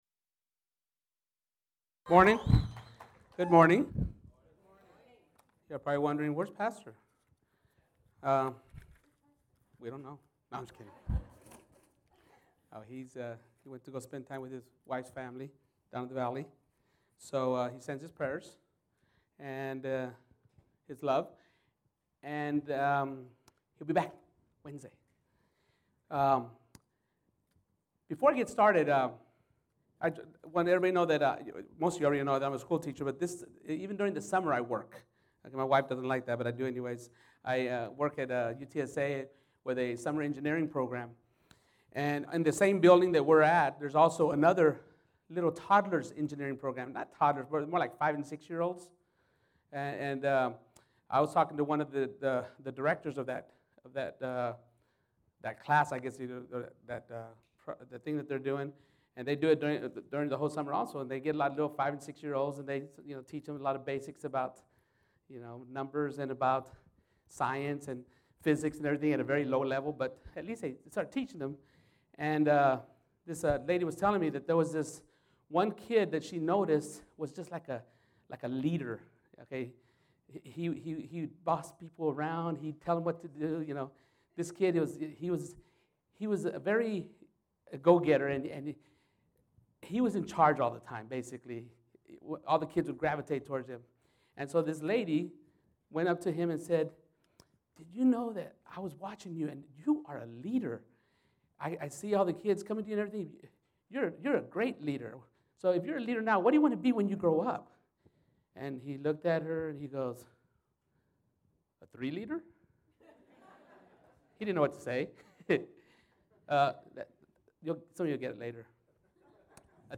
Sermons - Living Faith Church